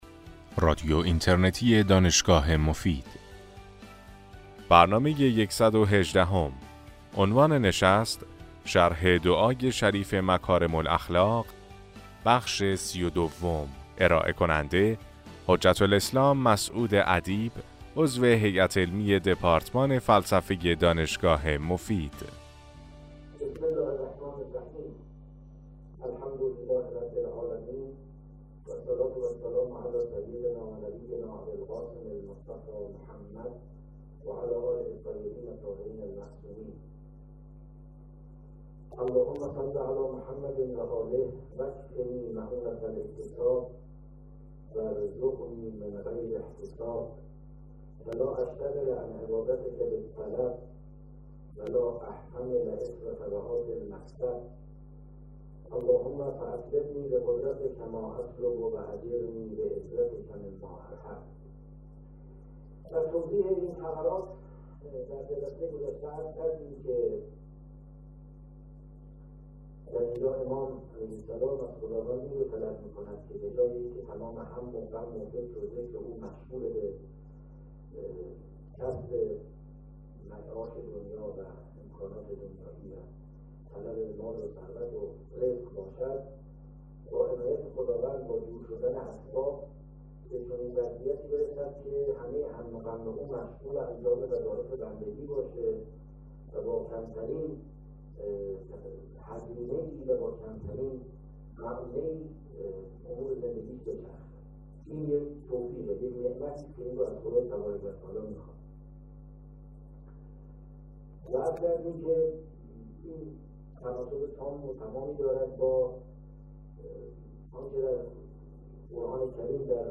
در این سلسله سخنرانی که در ماه رمضان سال 1395 ایراد شده است به شرح و تفسیر معانی بلند دعای مکارم الاخلاق (دعای بیستم صحیفه سجادیه) می پردازند.